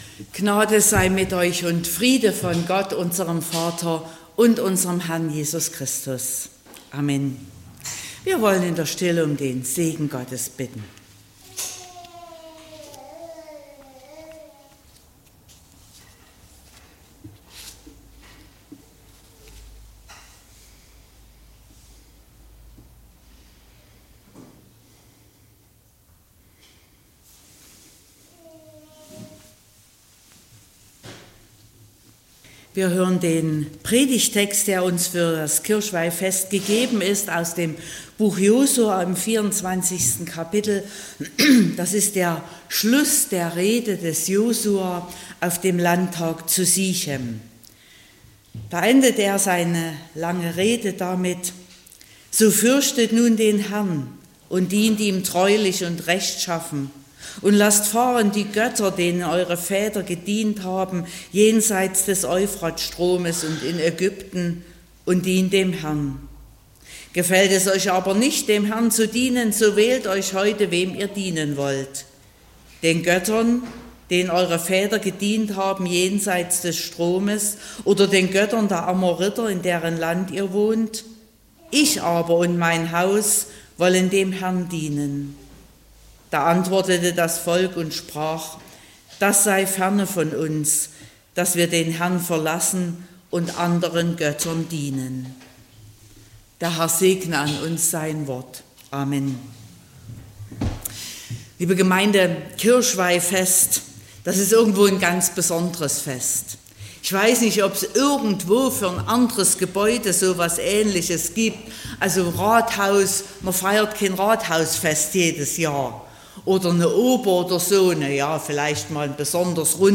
03.11.2024 – Kirchweihfestgottesdienst
Predigt (Audio): 2024-11-03_Kirchweihfest.mp3 (15,3 MB)